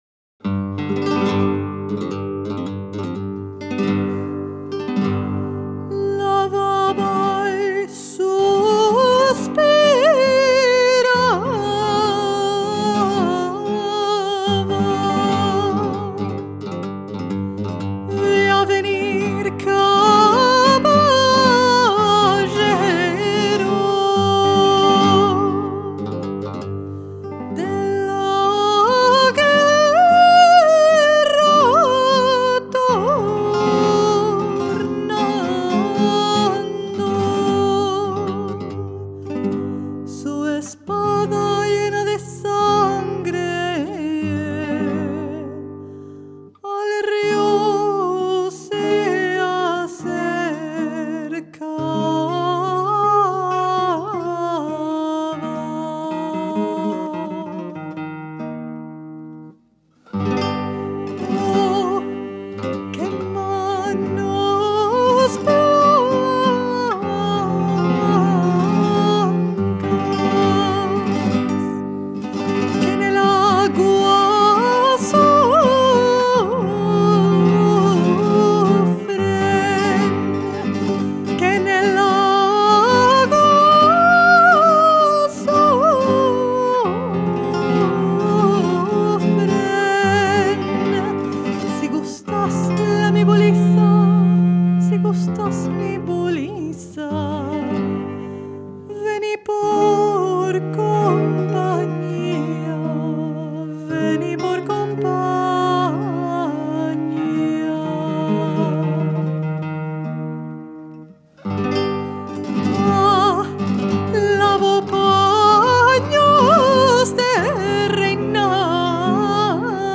Judeo-Moroccan music comes from the fusion of Moroccan and Andalusian cultures